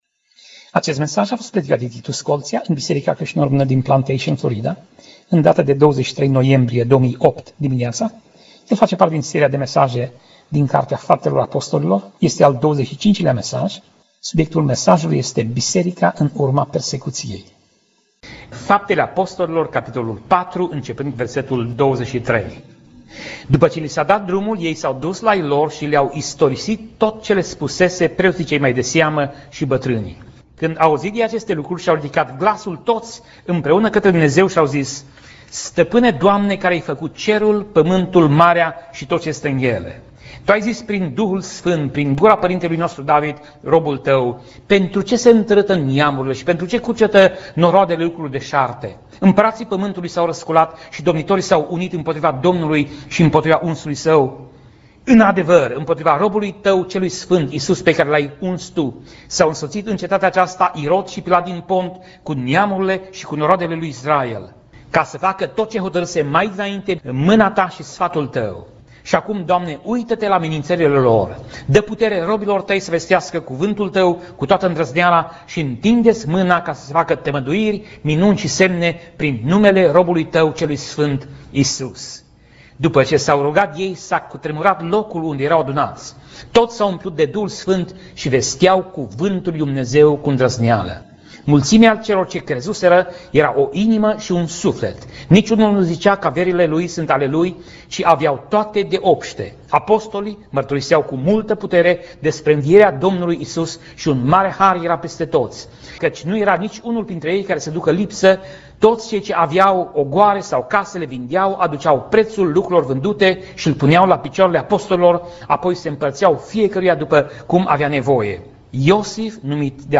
Pasaj Biblie: Faptele Apostolilor 4:23 - Faptele Apostolilor 4:35 Tip Mesaj: Predica